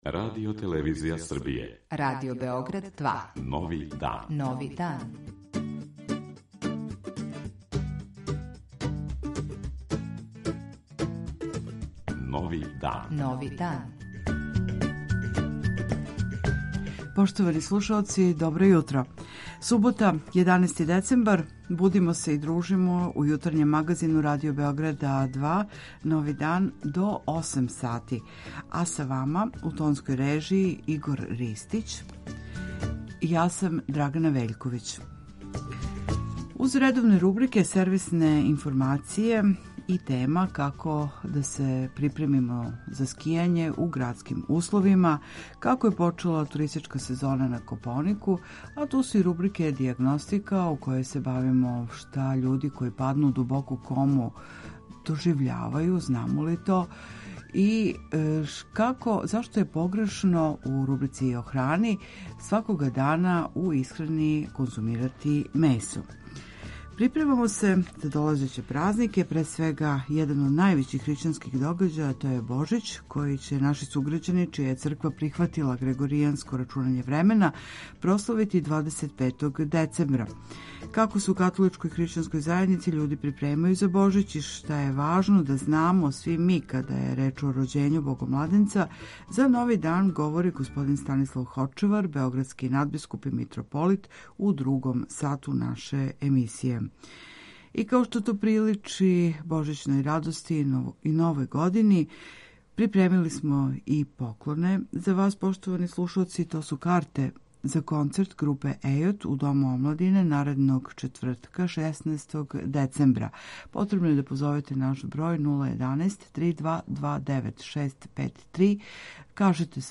Јутарњи викенд програм
Слушамо поуке преузвишеног надбискупа београдског и митрополита господина Станислава Хочевара.